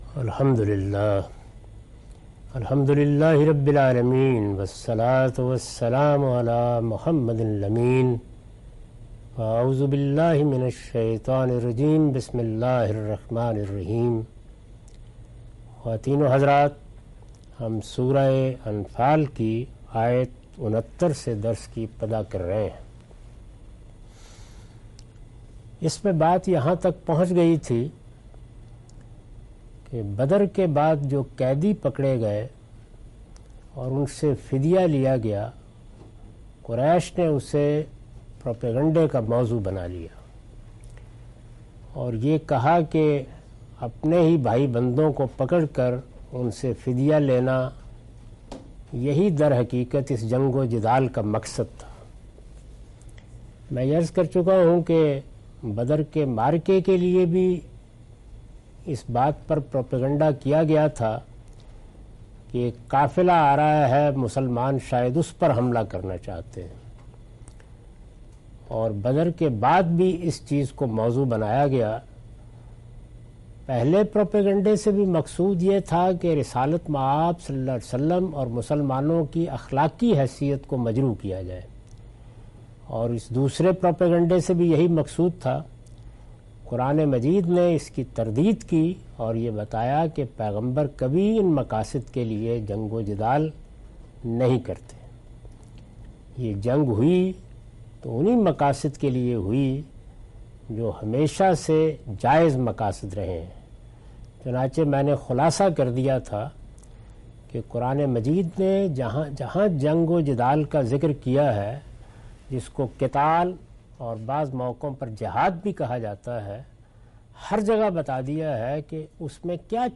Surah Al-Anfal - A lecture of Tafseer-ul-Quran – Al-Bayan by Javed Ahmad Ghamidi. Commentary and explanation of verses 69-72.